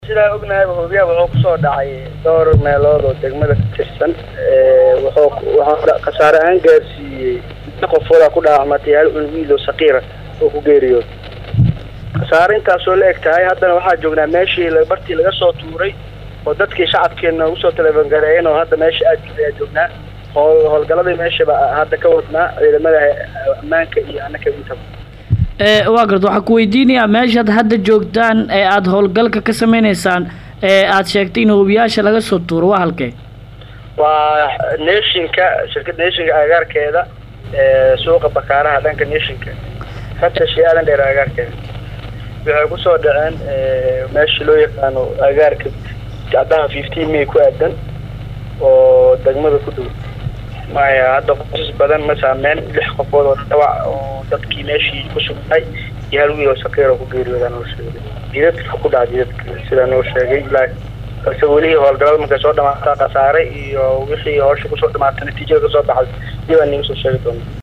DHAGEYSO-WAREYSI_-Maamulka-Degmada-Warta-Nabada-oo-ka-warbixiyay-khasaarihii-ka-dhashay-Madaafiicdii-Maanta-_.mp3